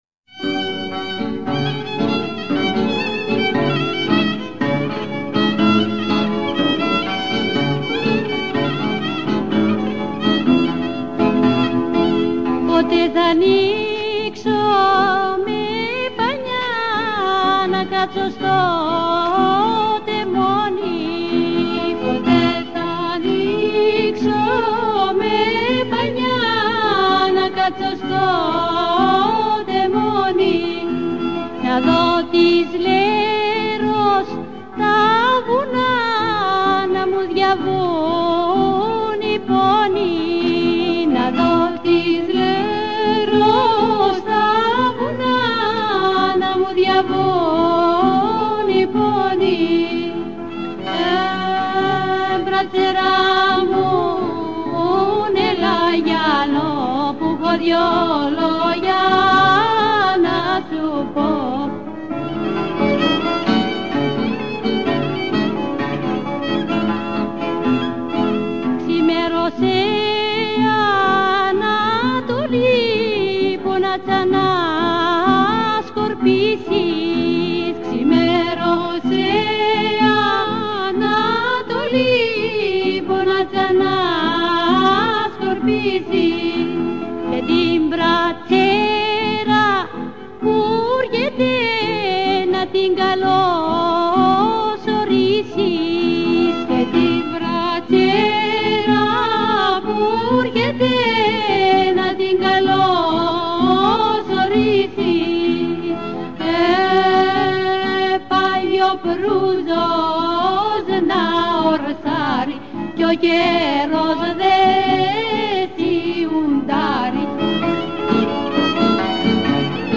ΤΡΑΓΟΥΔΙΑ ΚΑΙ ΣΚΟΠΟΙ ΑΠΟ ΤΑ ΔΩΔΕΚΑΝΗΣΑ